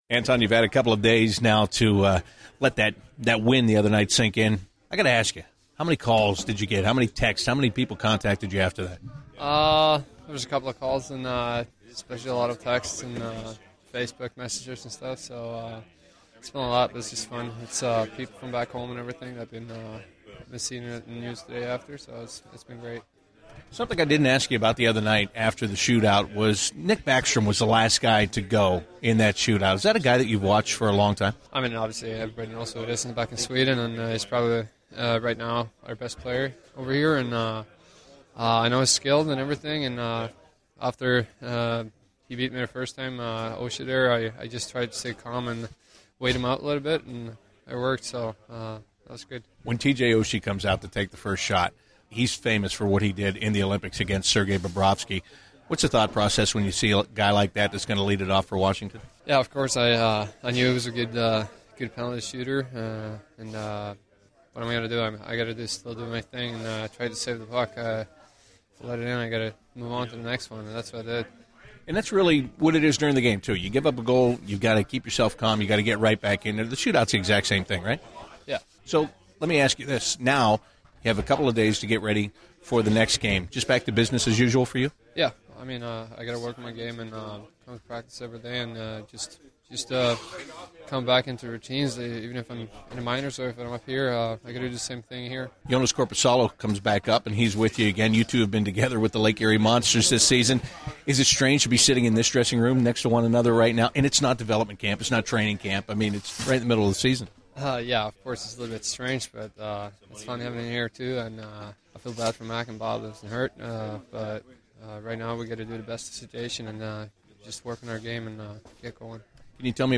Anton Forsberg Pre-Game 01/05/16